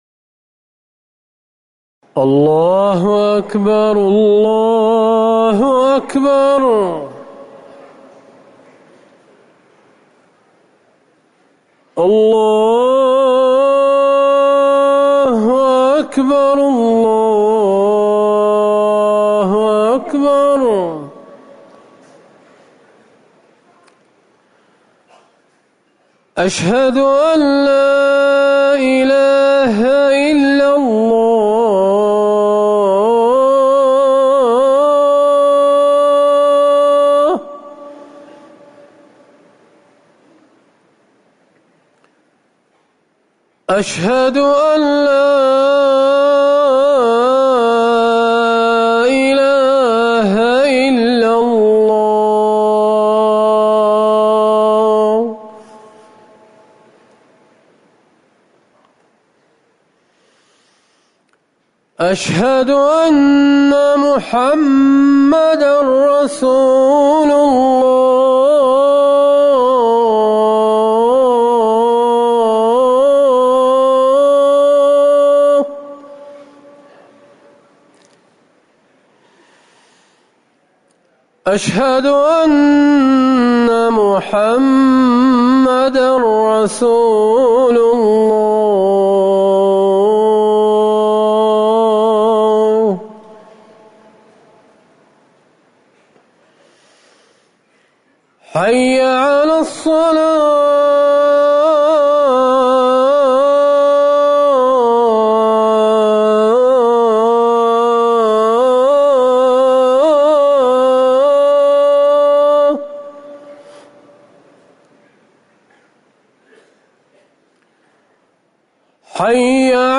أذان العصر
تاريخ النشر ٩ محرم ١٤٤١ هـ المكان: المسجد النبوي الشيخ